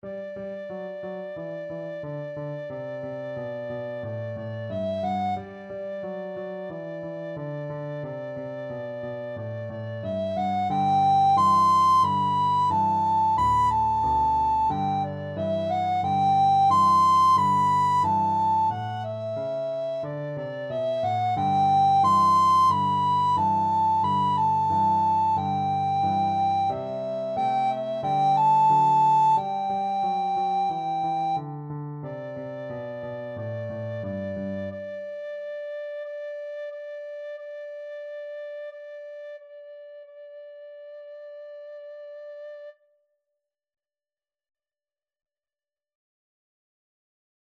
Free Sheet music for Soprano (Descant) Recorder
A beginners piece with a rock-like descending bass line.
March-like = 90
Pop (View more Pop Recorder Music)